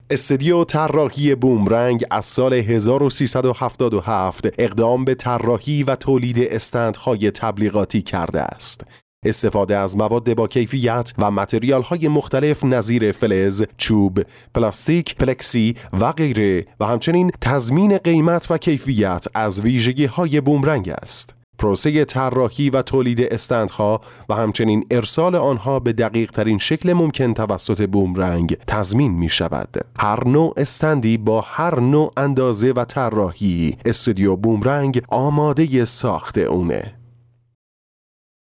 Male
Adult
Narration